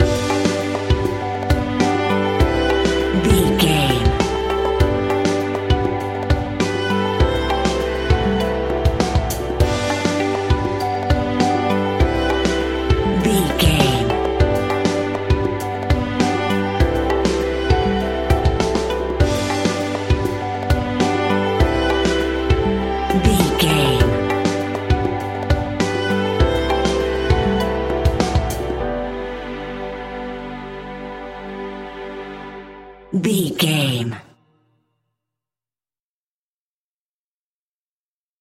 Ionian/Major
energetic
uplifting
indie pop rock music
upbeat
groovy
guitars
bass
drums
piano
organ